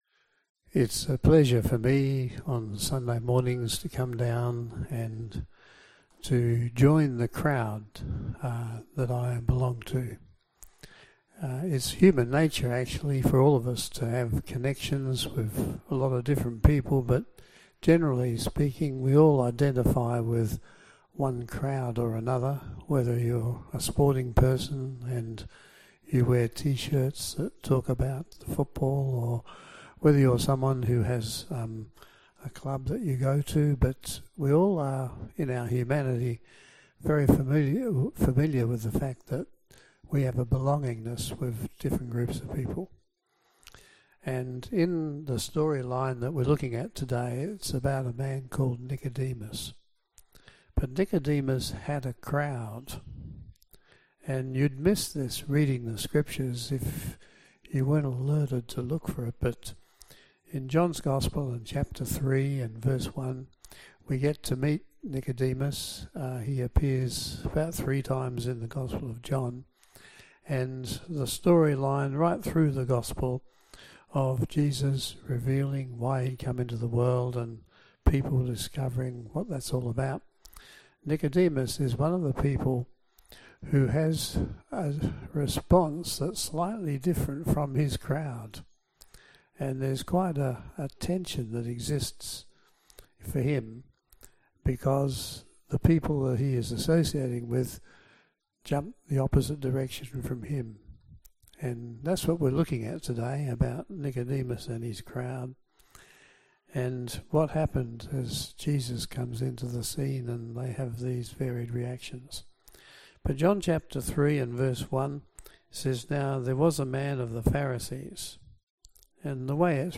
Passage: John 3:1-15, 7:45-52, 19:38-40 Service Type: AM Service